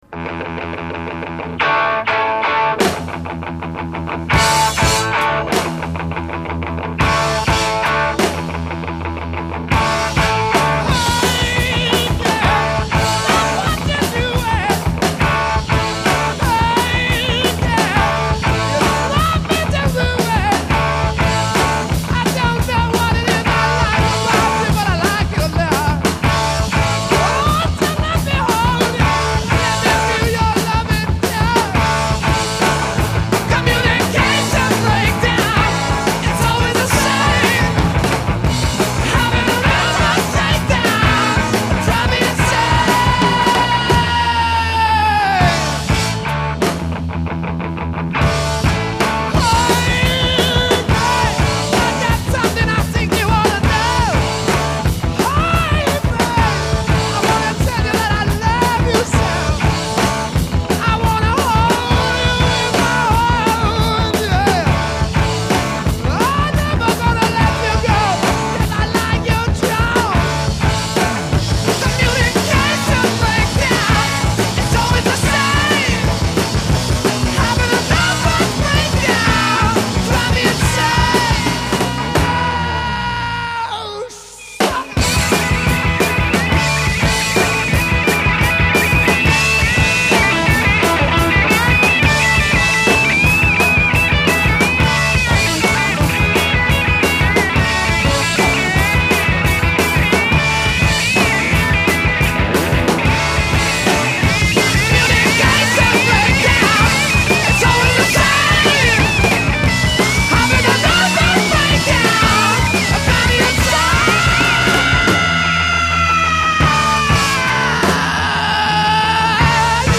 Recorded in October 1968 in Olympic Studios (Barnes)
acoustic and electric guitars and vocals
drums
Introduction   Guitar, add bass and drums.
Verse 16 Solo vocal over backing track a
Refrain 4 As in Refrains above, but add guitar solo b
Coda 16+ Repeat hook over guitar intro material, and fade d